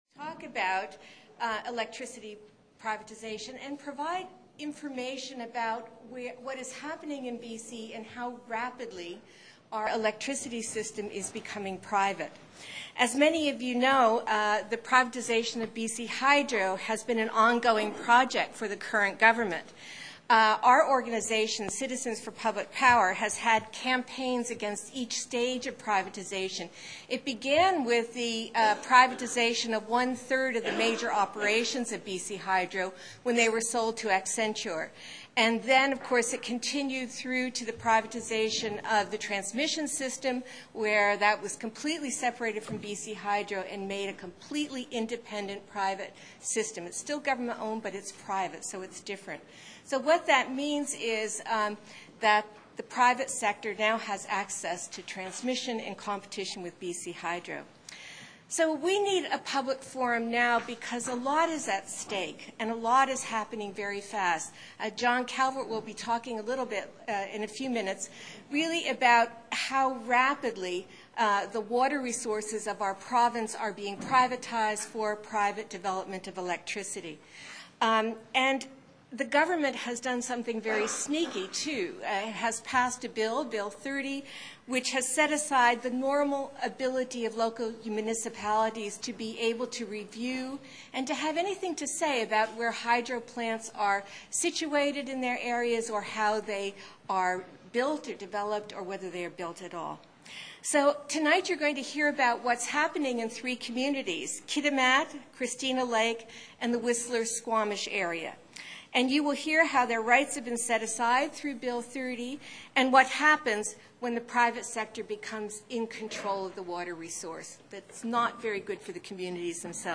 "Selling Us Down the River: What's at stake by privatizing BC's Run-of-the-River energy resources?" Public Forum, October 21st, 2006 @ the Vancouver Public Library, sponsored by BC Citizens for Public Power and the Council of Canadians.